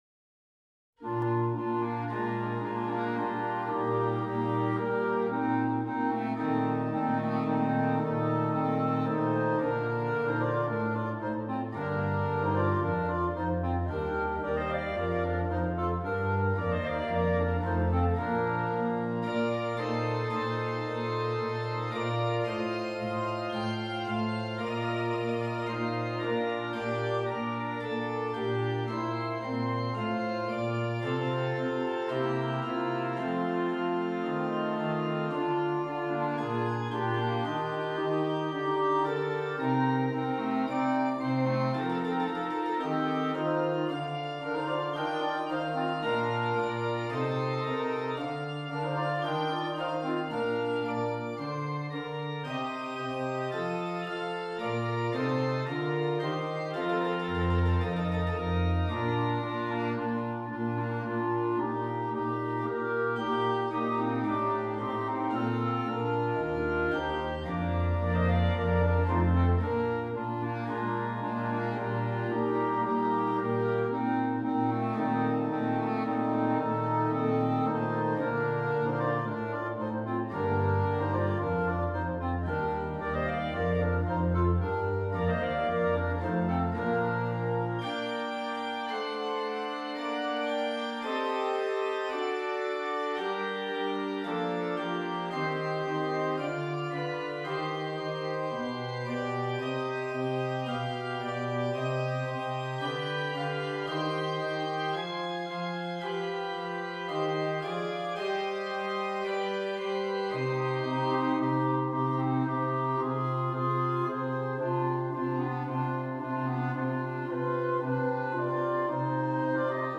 5 Clarinets, Bass Clarinet